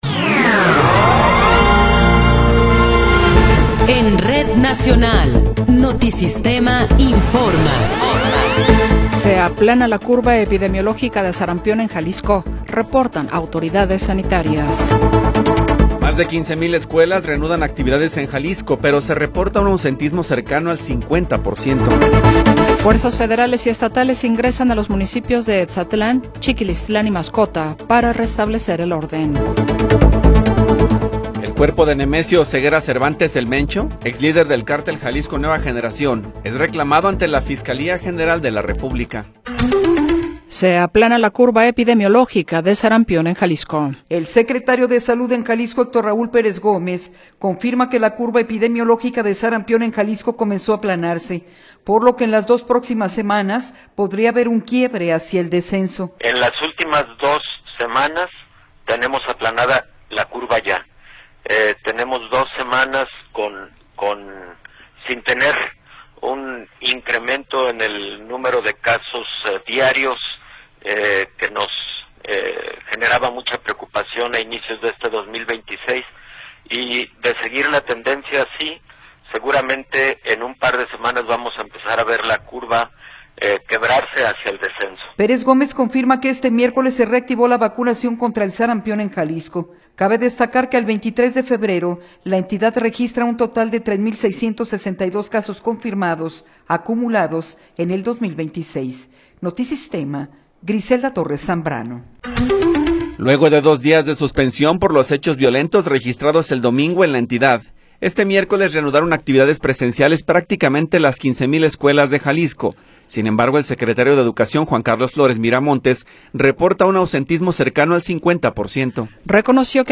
Noticiero 14 hrs. – 25 de Febrero de 2026